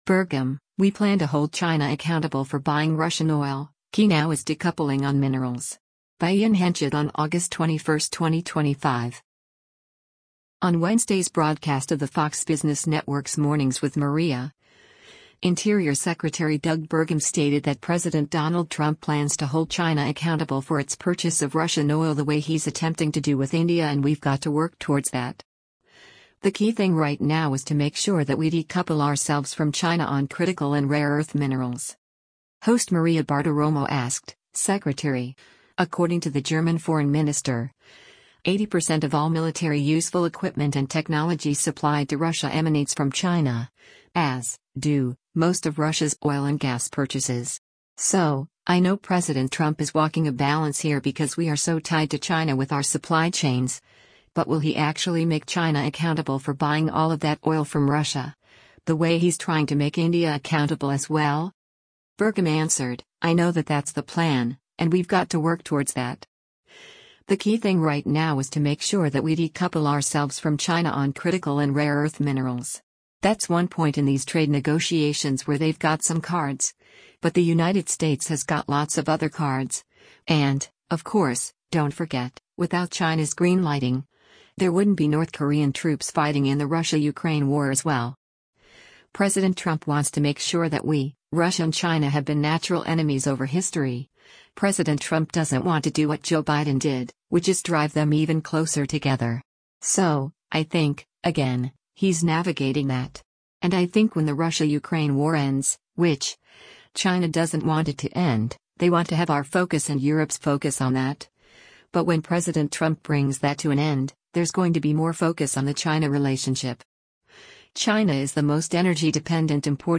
On Wednesday’s broadcast of the Fox Business Network’s “Mornings with Maria,” Interior Secretary Doug Burgum stated that President Donald Trump plans to hold China accountable for its purchase of Russian oil the way he’s attempting to do with India “and we’ve got to work towards that. The key thing right now is to make sure that we decouple ourselves from China on critical and rare earth minerals.”